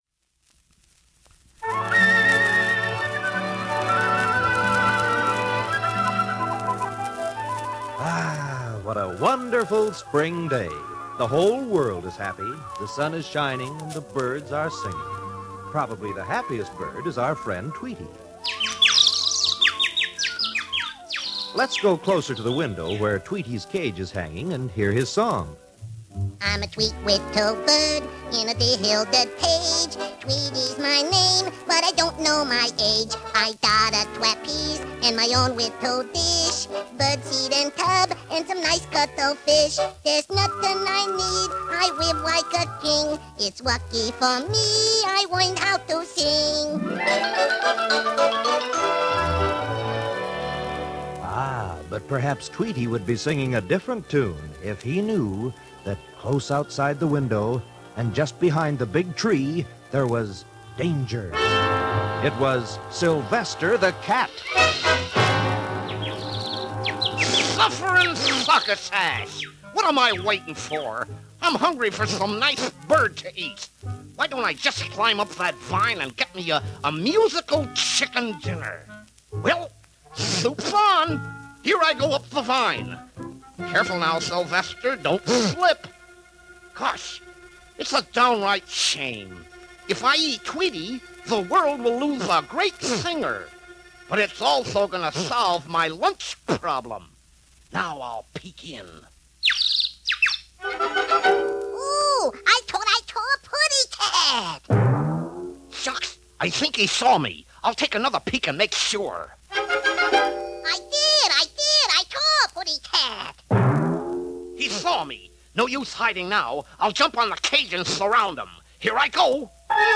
record from the 1950's